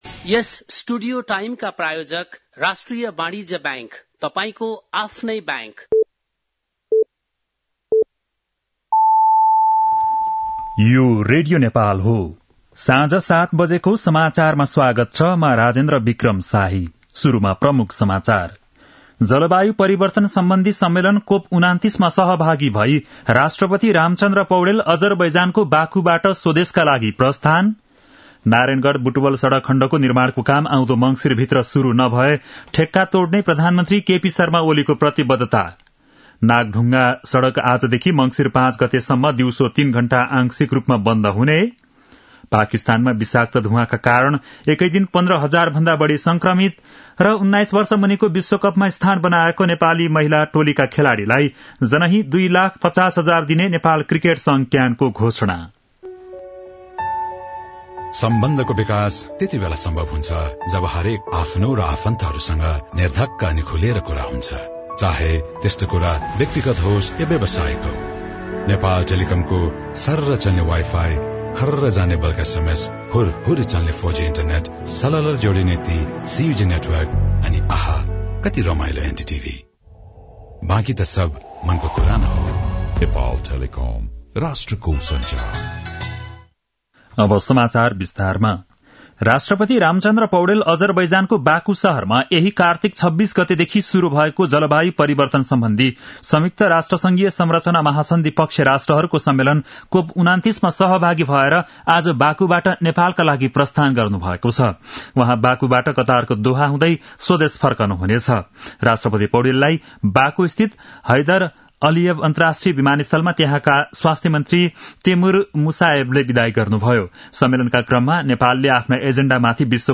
बेलुकी ७ बजेको नेपाली समाचार : ३० कार्तिक , २०८१
7-pm-nepali-news-7-29.mp3